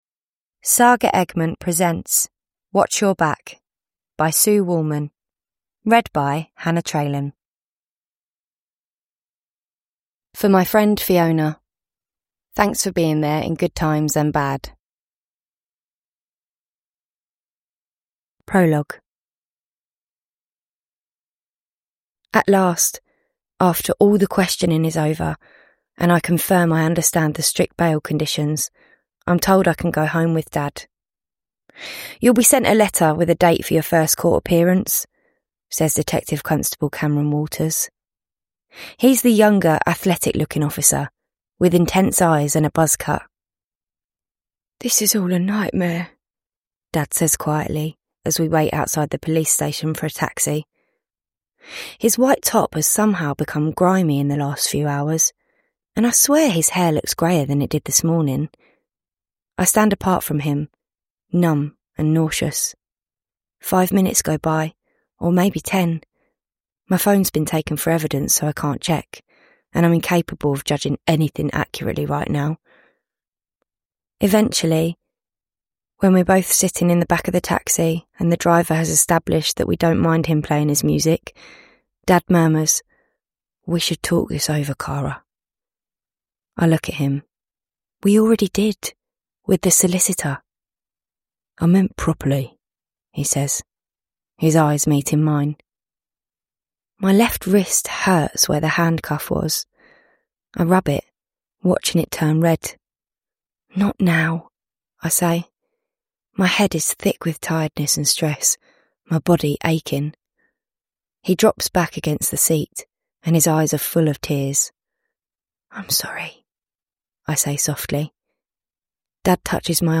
Watch Your Back: A nail-biting YA thriller – Ljudbok